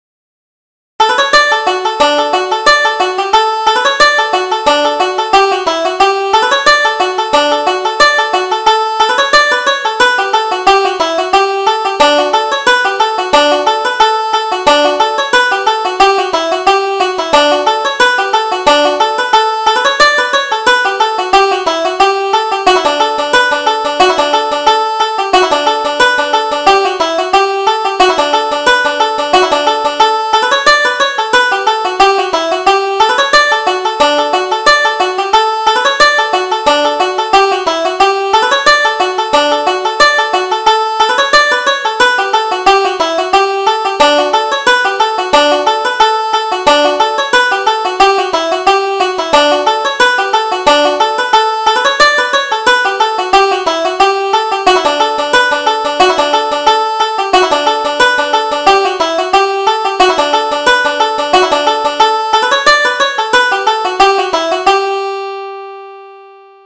Reel: Jennie Rock the Cradle